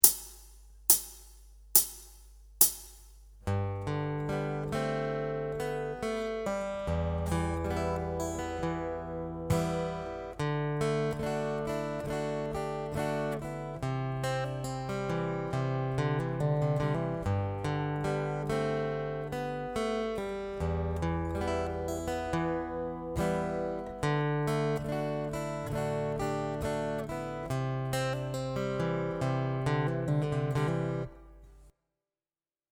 Acoustic Rhythm Guitar Chord Embellishment 2
As you can hear in the example above, using extensions of the chord really adds to the embellishing.
This is especially true with the C chord in the progression above.